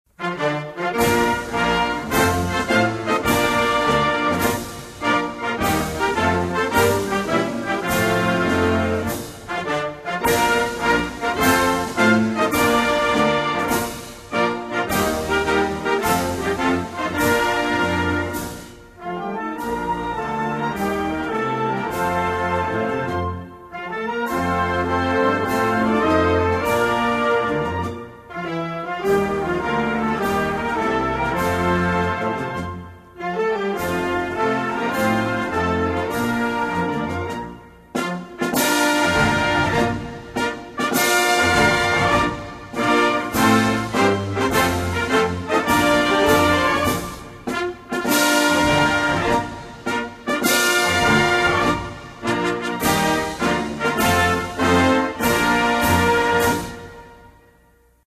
Cameroon_anthem.mp3